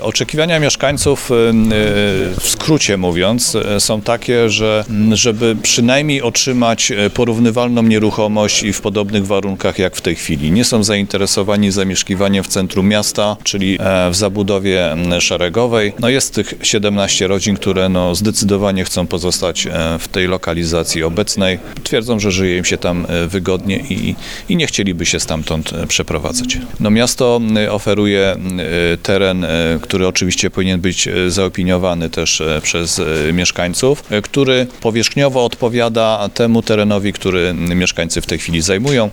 Mówi Krzysztof Bagiński, sekretarz Świnoujścia.